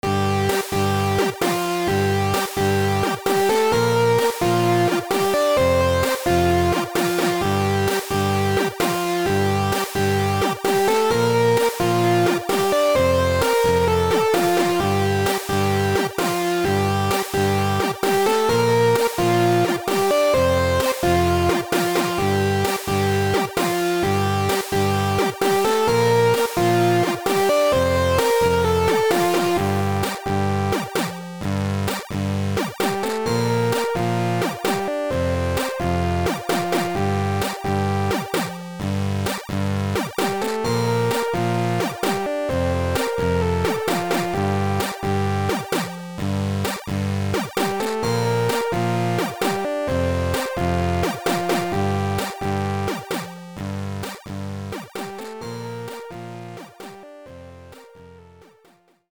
Jumping Lands [Chiptune
Its a short Chiptune Song, I created and I will use this year for the & ...